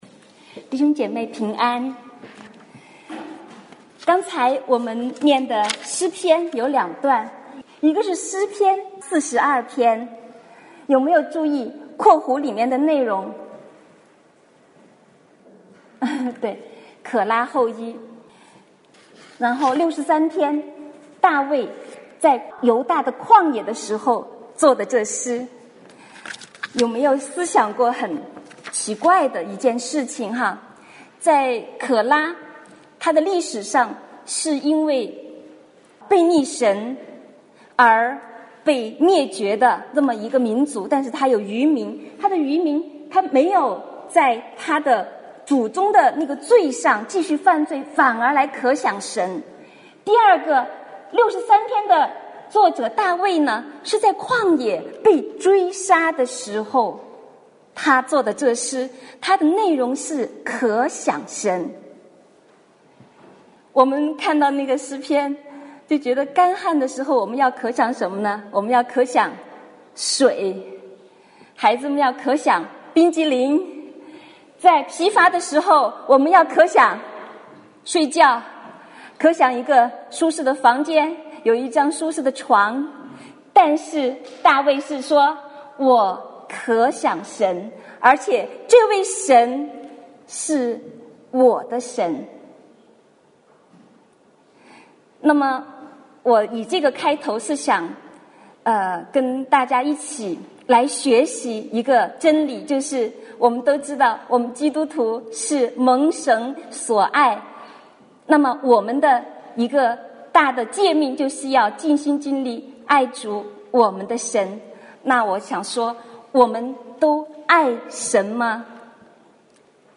主日分享音频